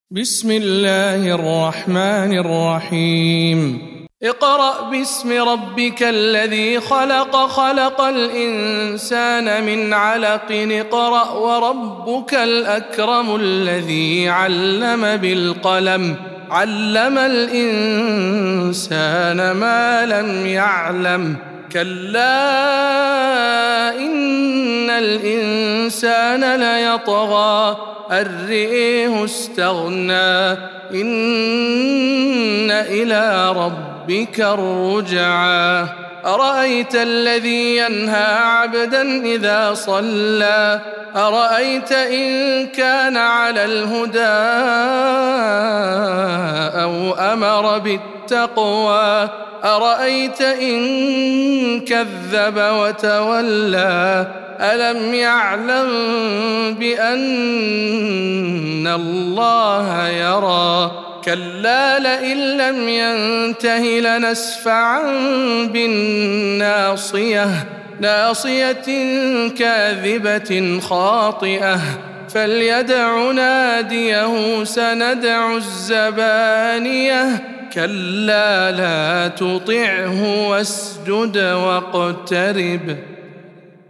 سورة العلق - رواية ابن ذكوان عن ابن عامر